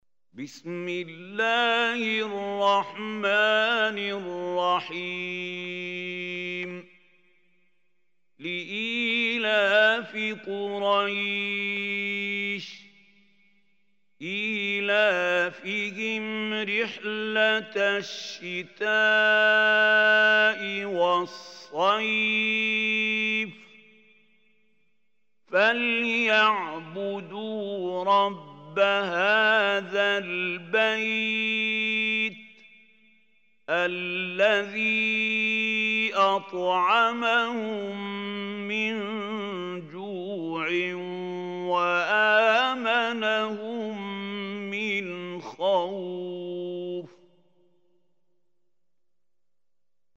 Surah Quraysh MP3 Recitation by Mahmoud Khalil
Surah Quraysh is 106 surah of Holy Quran. Listen or play online mp3 tilawat / recitation in Arabic in the beautiful voice of Sheikh Mahmoud Khalil Hussary.
106-surah-quraish.mp3